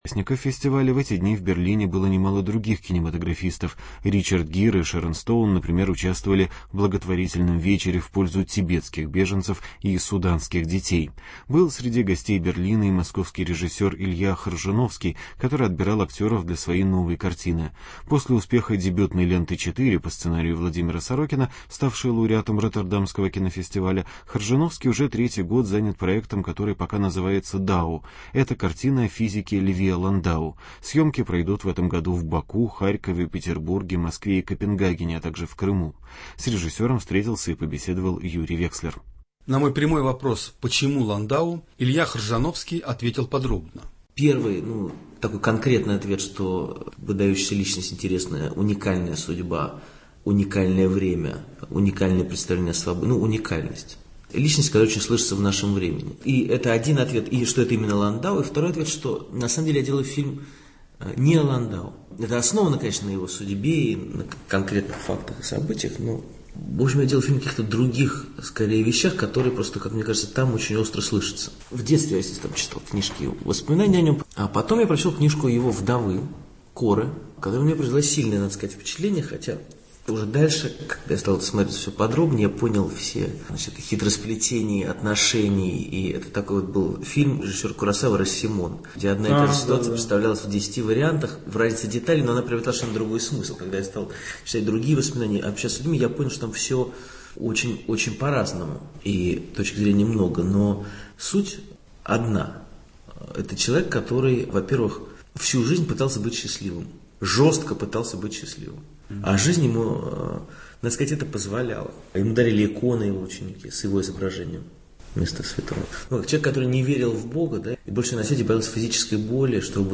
Интервью с кинорежиссером Ильей Хржановским.